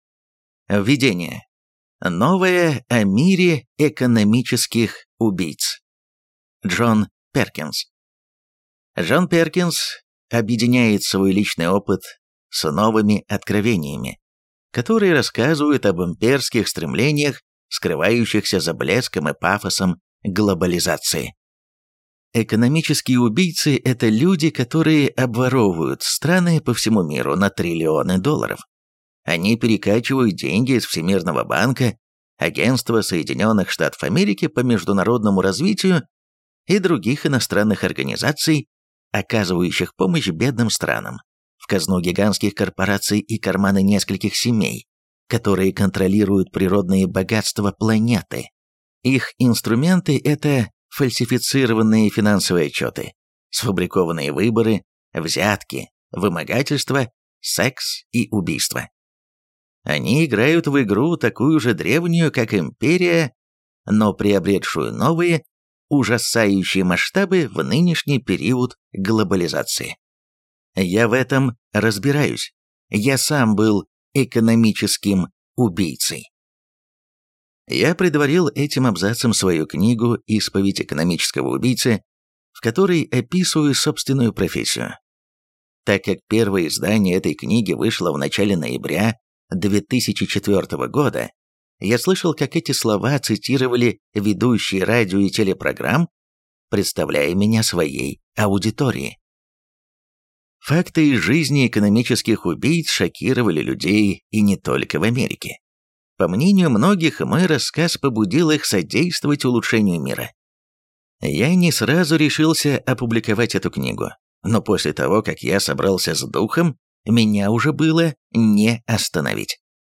Аудиокнига Игра, старая как империя | Библиотека аудиокниг